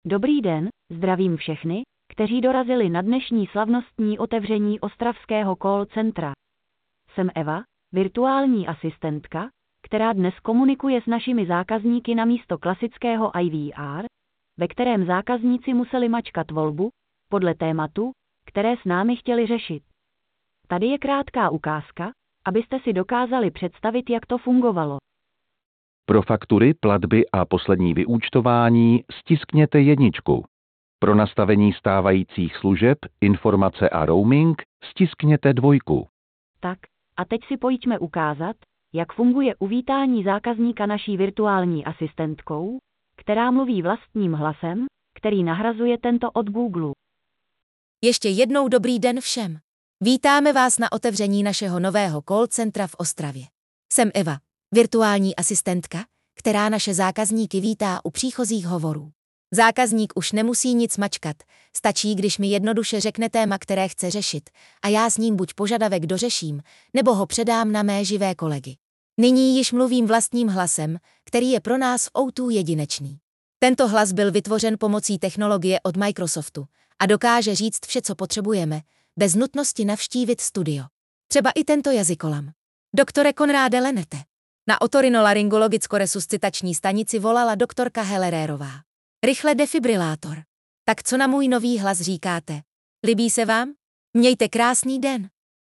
O2 je první firmou v České republice, která se ve spolupráci se společností Microsoft rozhodla jít cestou vlastního vytvořeného hlasu virtuální asistentky. Prvním úkolem bylo načíst ve studiu 2 000 náhodných vět, a to hlasem herečky Kateřiny Winterové.
Následně jsme desítky hodin prostřednictvím neuronové sítě hlas programovali a trénovali.
Ukázka hlasu virtuální asistentky